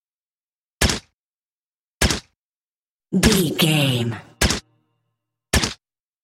Filled with 5 sounds(44/16 wav.) of Pistol Firing(Five single shots) with silencer.
Pistol Firing with Silencer 06
Sound Effects
Adobe Audition, Zoom h4
muted
handgun